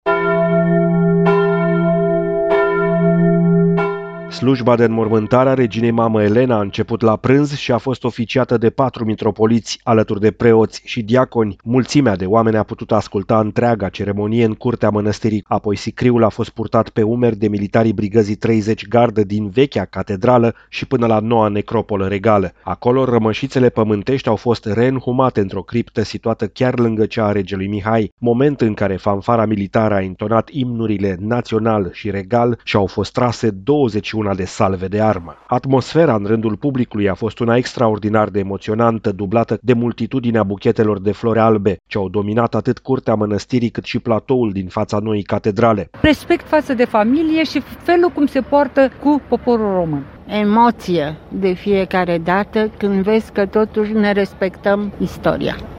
Regina a fost omagiată ieri de mii de oameni strânşi la Curtea de Argeş.